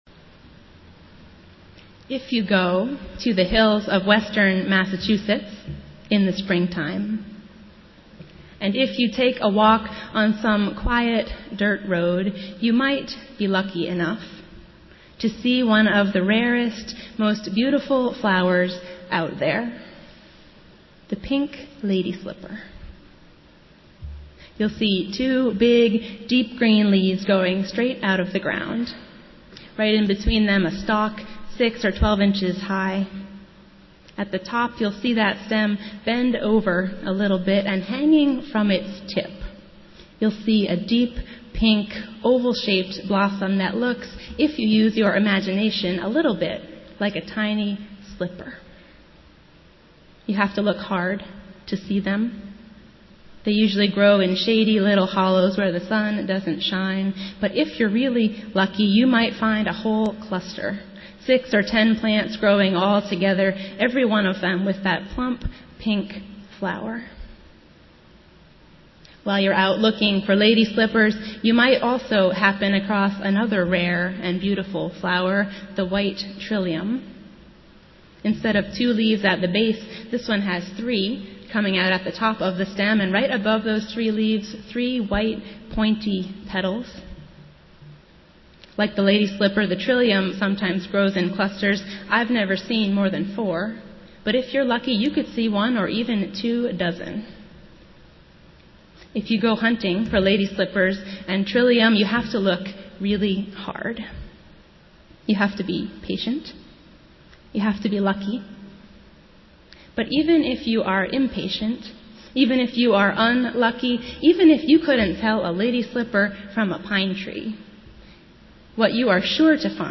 Festival Worship - Fourteenth Sunday after Pentecost